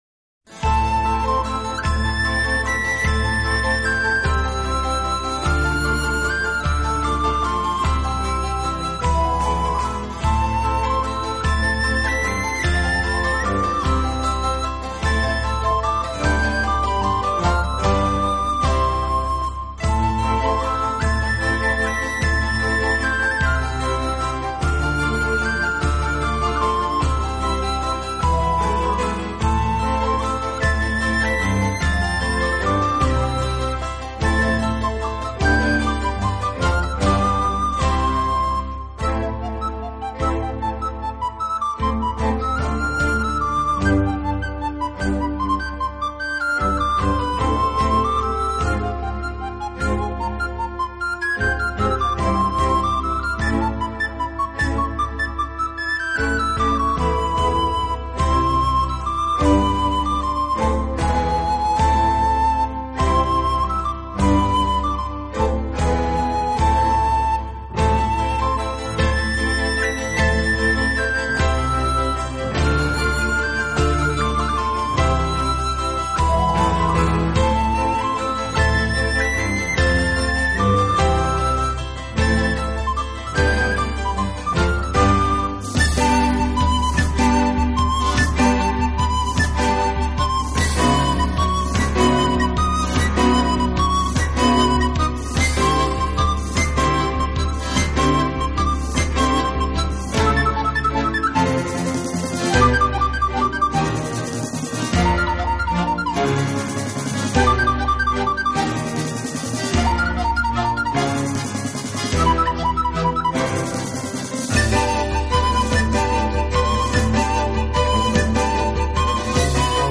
加上精心设计的模拟乐团合成乐器伴奏，整个气氛像极了中世纪修道 院的宁静安详，悠扬的旋律却又展现世俗华丽的美感